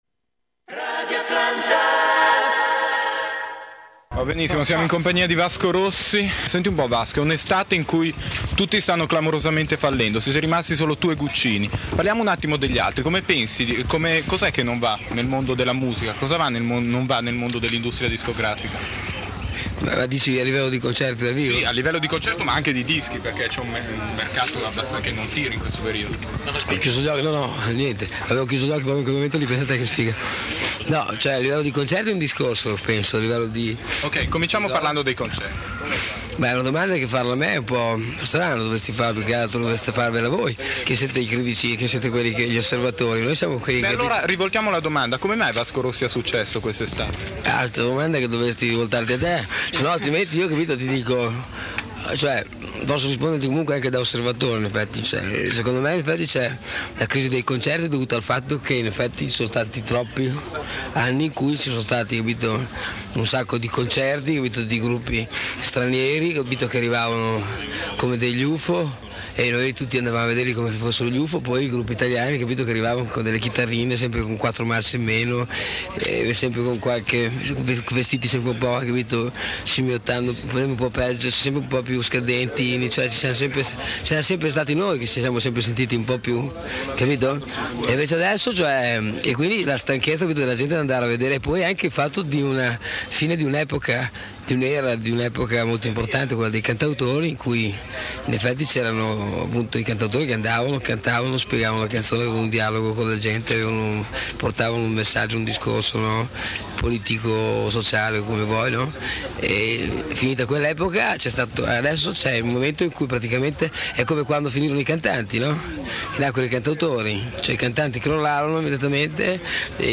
Intervista di Radio Atlanta Milano a Vasco Rossi al Festivalbar 1983
Vasco Rossi - Intervista Festivalbar 1983.mp3